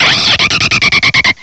Add all new cries
cry_not_noivern.aif